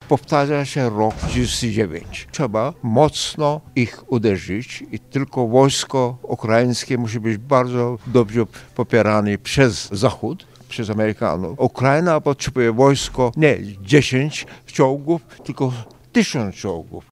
Problemy prawne związane z udzielaniem pomocy Ukrainie są tematem przewodnim międzynarodowej konferencji organizowanej na Katolickim Uniwersytecie Lubelskim.